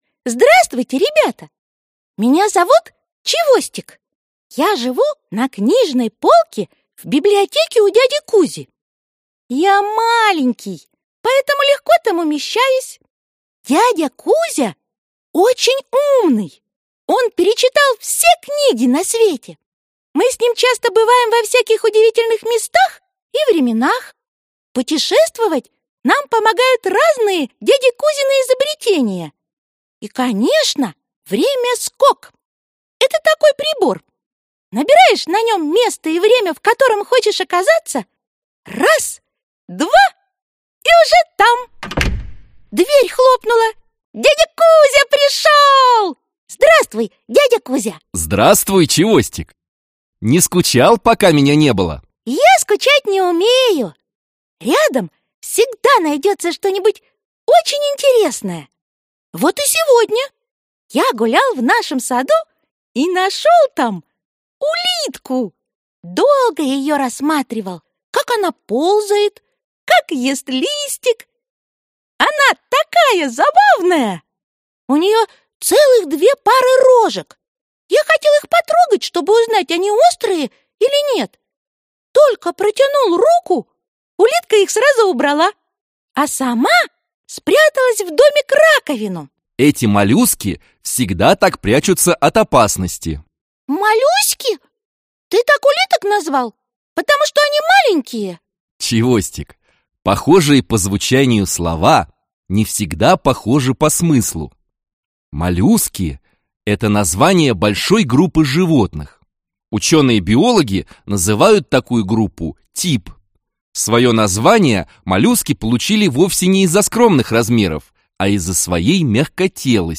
Аудиокнига Осьминоги и другие моллюски | Библиотека аудиокниг